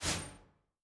Swing sword_2.wav